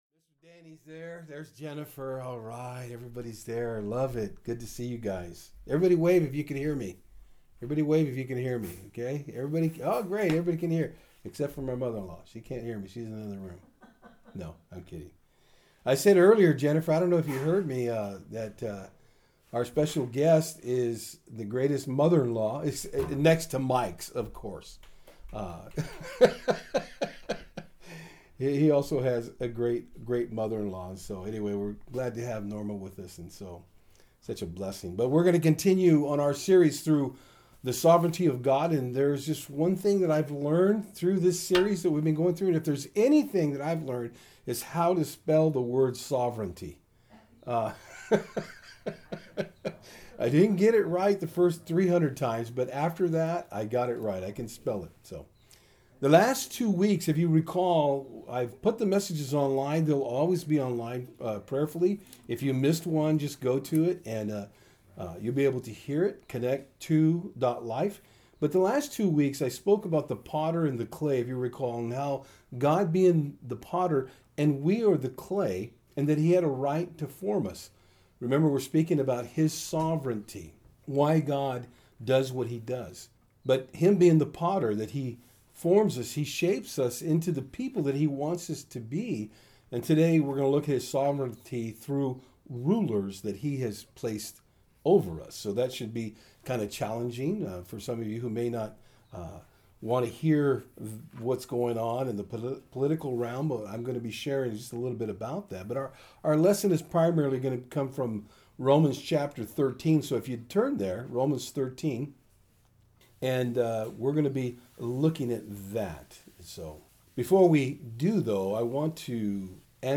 Service Type: Sundays @ Fort Hill